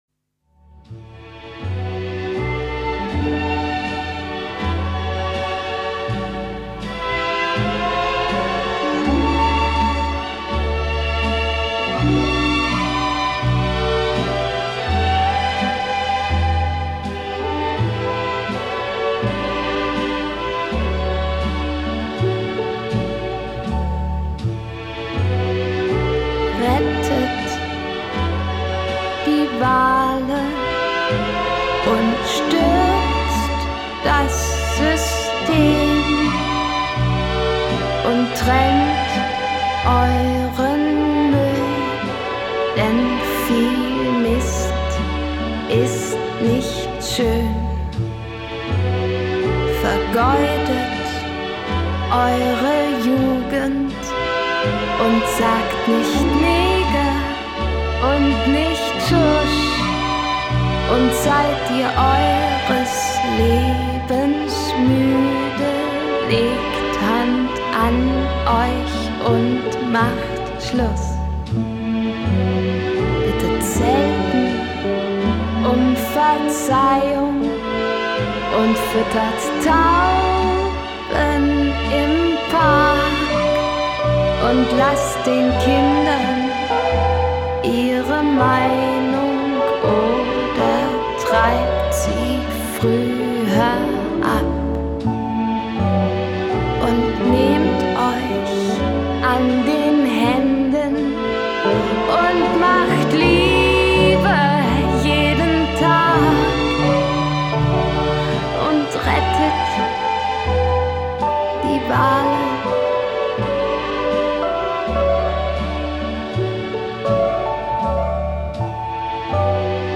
- wir brechen mit einer alten Tradition und lehnen uns gegegen das Establishment auf UND können einen neuen Eintrag in die AN(n)ALEN vornehmen (akkustische Unterstützung beim Bruch mit Traditionen mag dieses hübsche Protestliedchen geben: